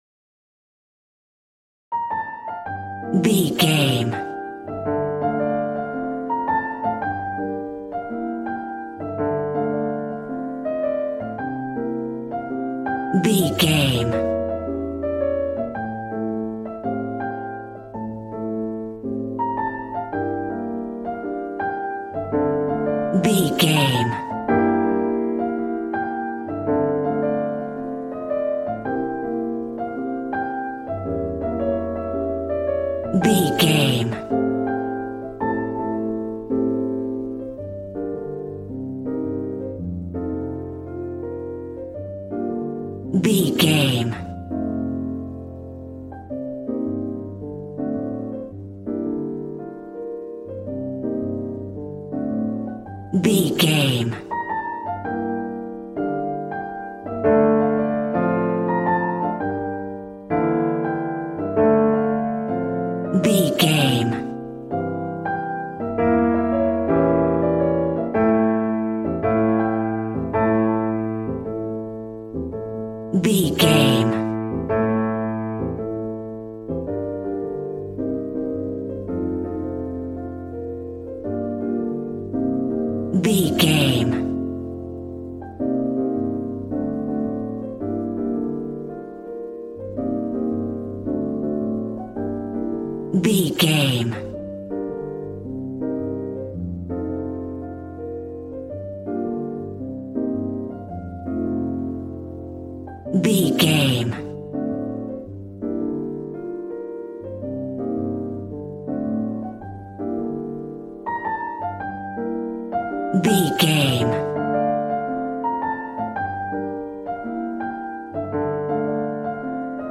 Aeolian/Minor
sexy
smooth
piano
drums